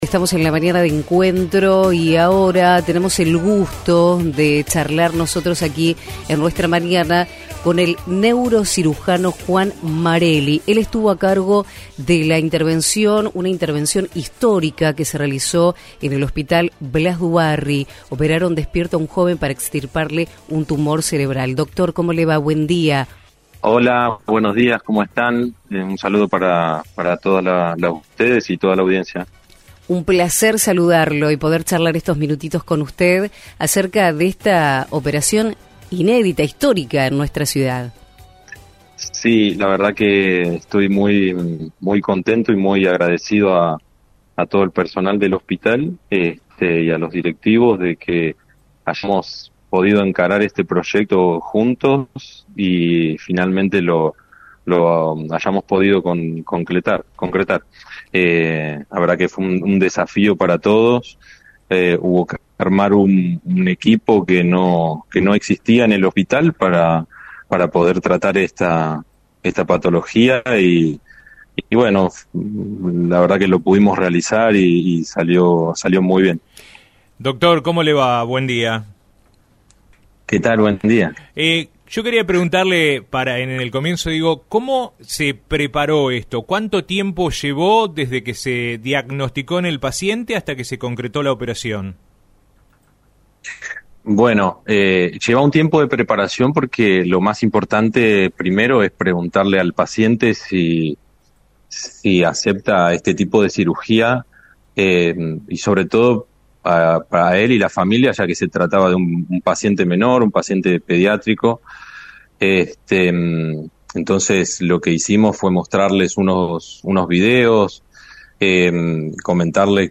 Nota realizada en Radio Universo 93.1